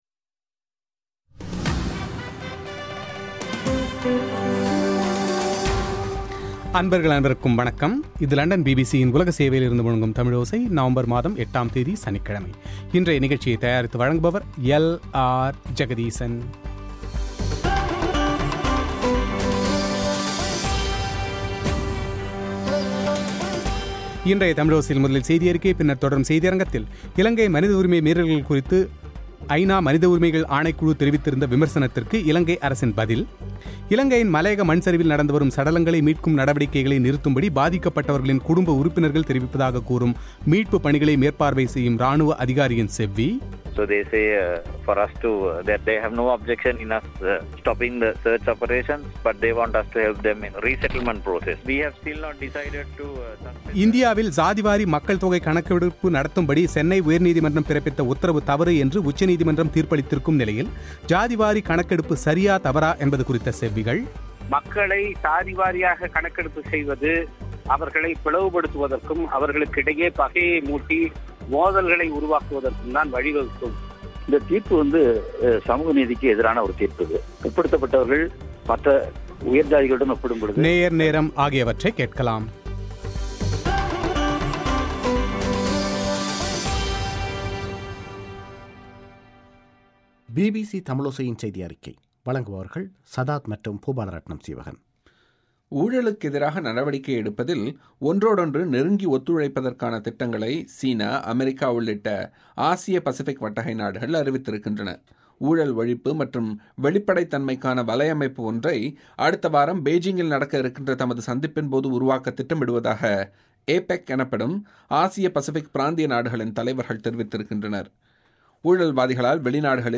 விரிவான செவ்விகள்